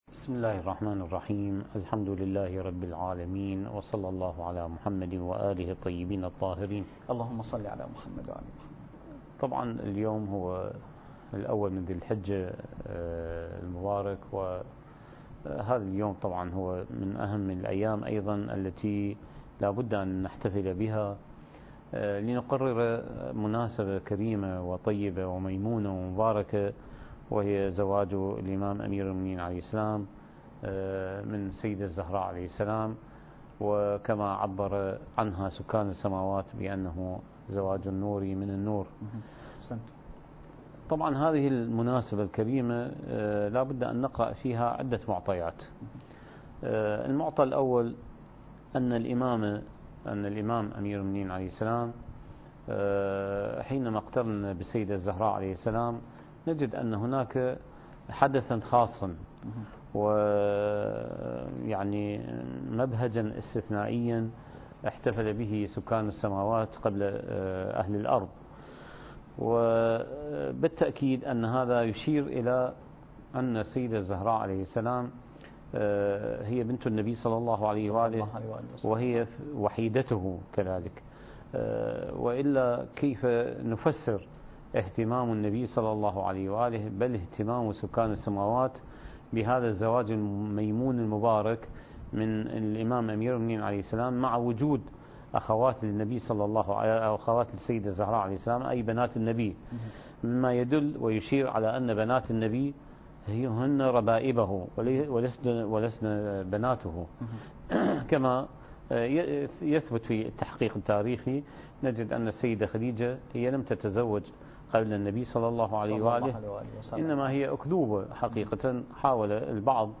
مکان: تهران میدان امام حسین (علیه السلام)